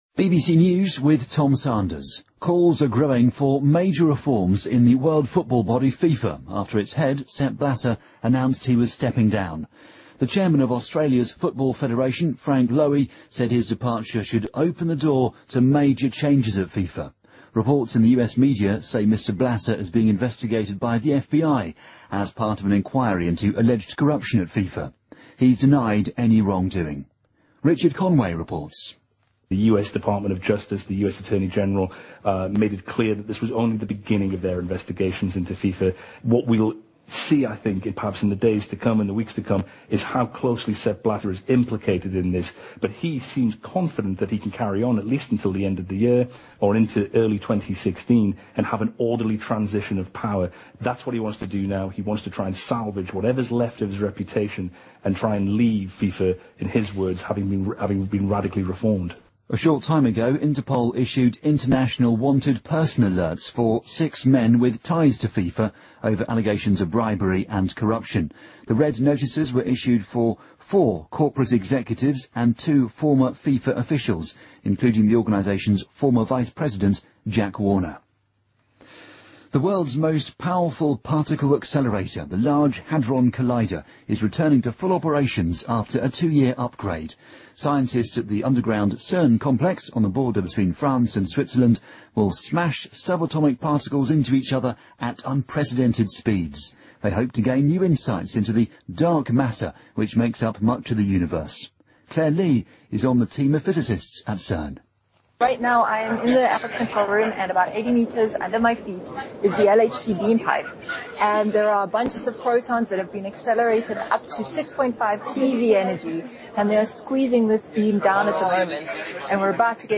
BBC news,韩国200多学校停课应对中东呼吸综合征疫情